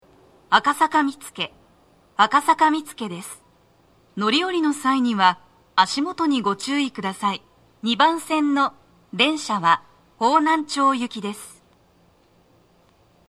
足元注意喚起放送が付帯されています。
女声
到着放送2